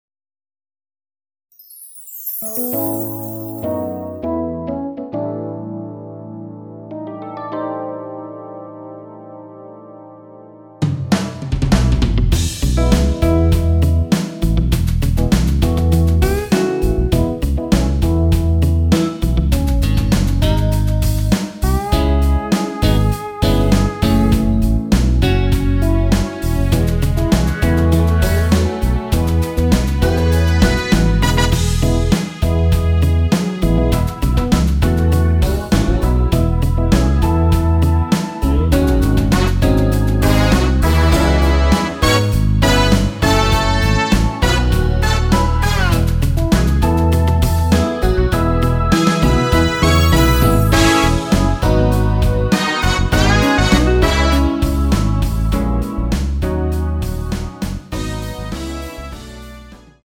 원키에서(-1)내린 MR입니다.(미리듣기 확인)
앞부분30초, 뒷부분30초씩 편집해서 올려 드리고 있습니다.
중간에 음이 끈어지고 다시 나오는 이유는